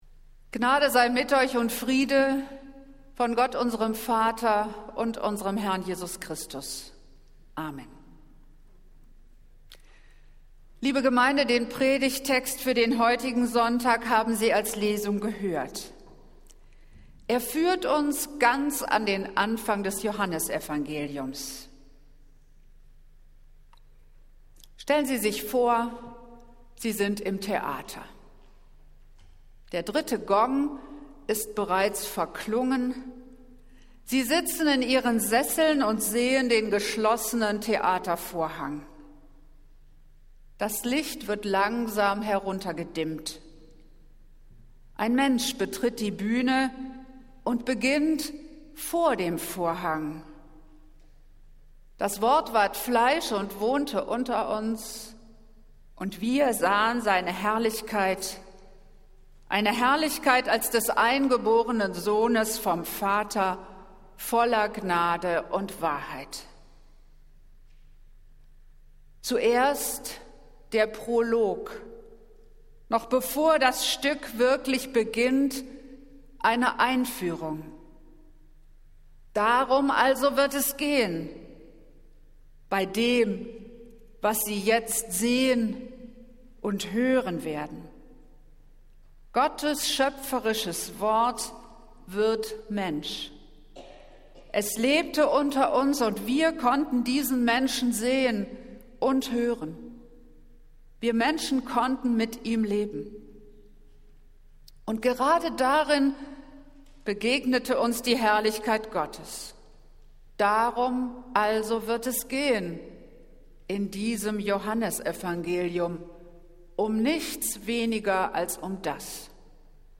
Predigt des Gottesdienstes aus der Zionskirche am Sonntag, den 9. Juli 2023